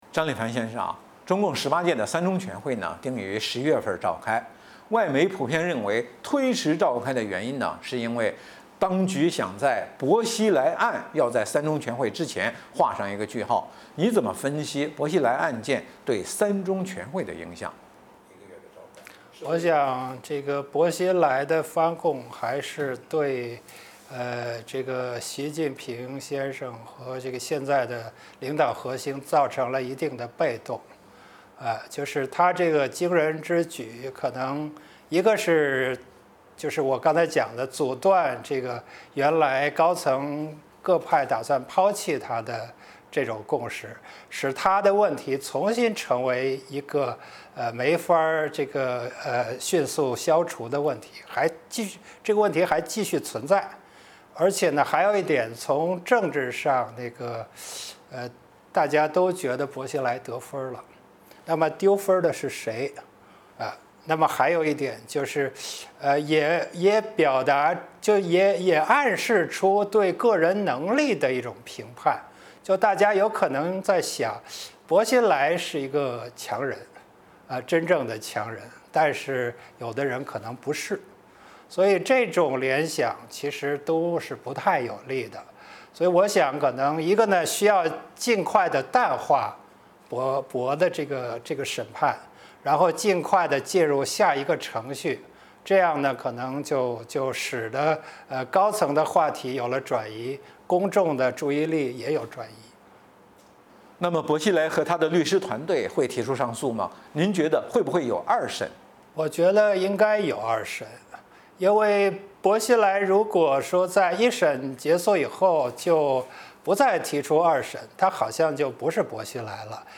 专访章立凡 (三)：薄熙来翻供阻断北戴河共识 东山再起取决于三条曲线